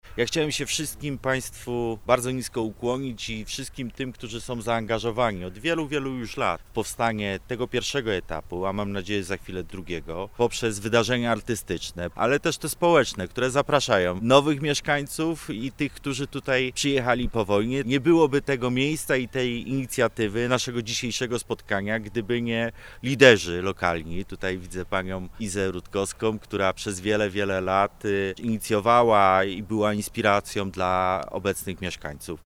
O przebiegu inwestycji i oddolnym zaangażowaniu mówi Jakub Mazur, wiceprezydent Wrocław.